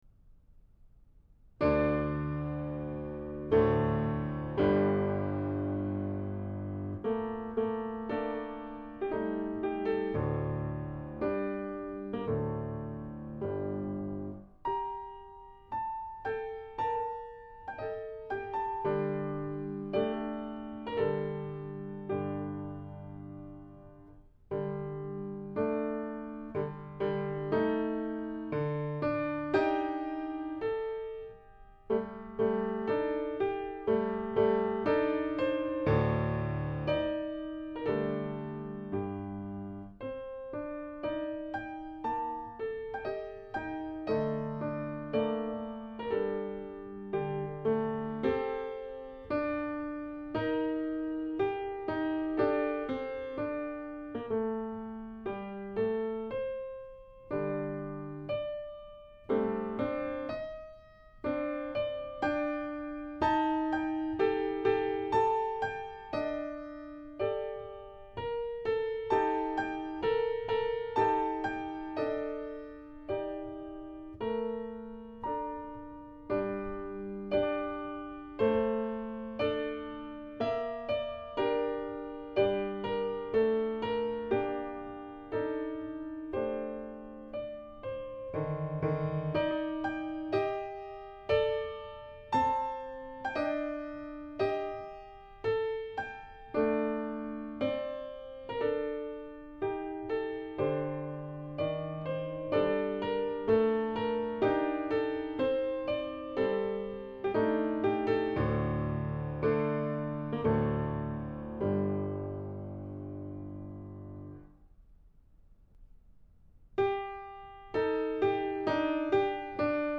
♪mp3 筝的ヴァージョン♪※2
今回は試みに、ピアノ、箏それぞれの楽器の奏法を意識した2つのヴァージョンにより録音した。
※1 ピアノ的ヴァージョンは横に流れる旋律が意識されるが、※2 箏的ヴァージョンでは縦の音の重なりやリズムが強調されて聴こえる。また、箏爪で弦をはじく音色がピアノでもかなり再現できることがわかったのは発見であった。